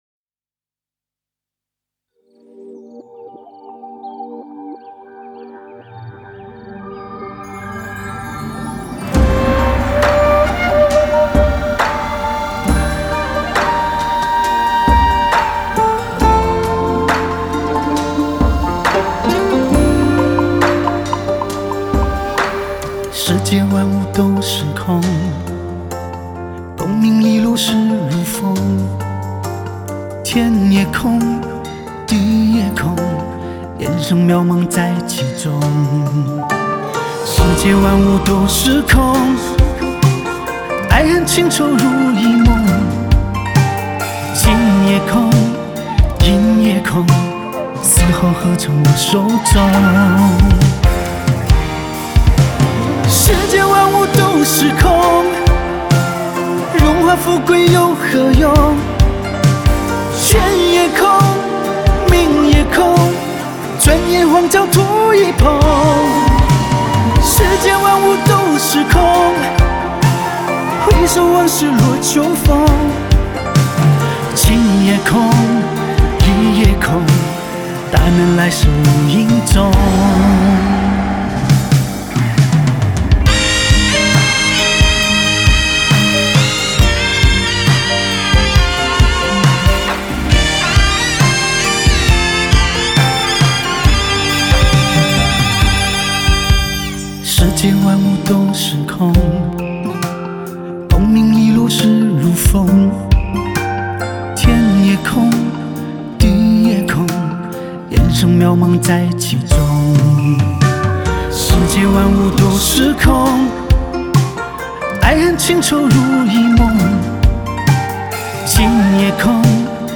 Ps：在线试听为压缩音质节选，体验无损音质请下载完整版
吉他
唢呐
录音棚：上海魔都录音室